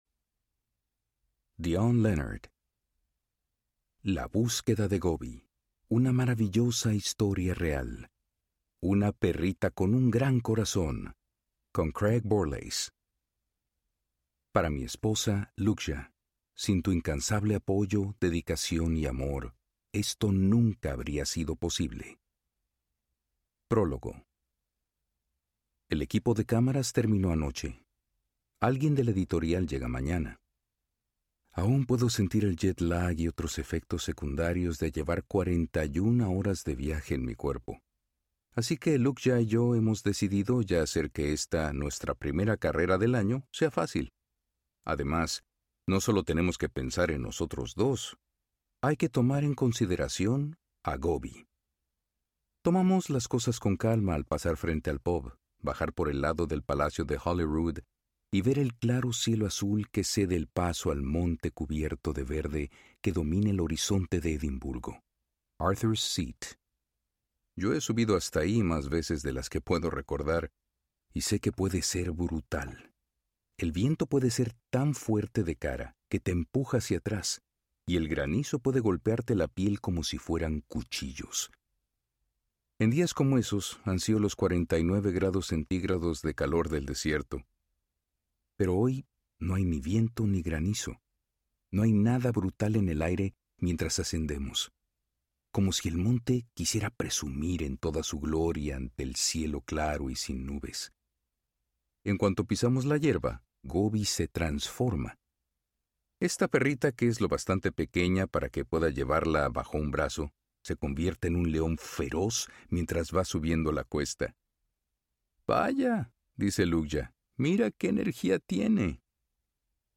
La búsqueda de Gobi Audiobook
Narrator
7.2 Hrs. – Unabridged